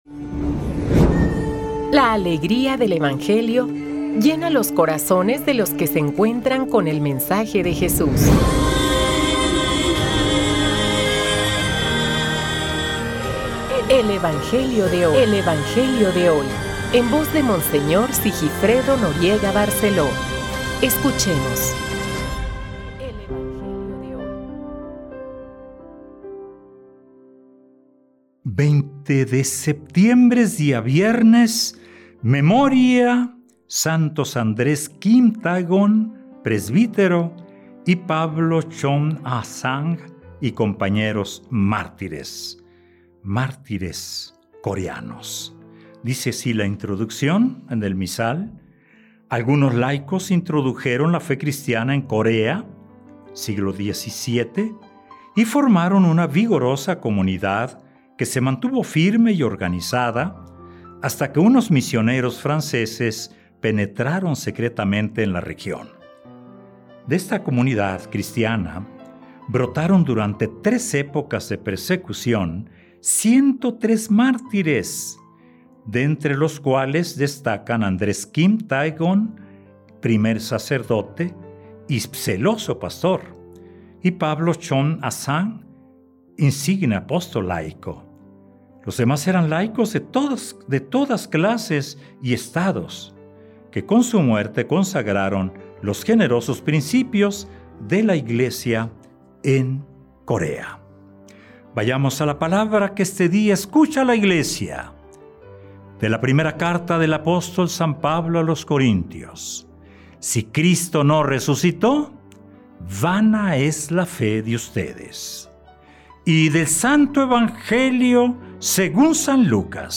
Lectura del santo evangelio según san Lucas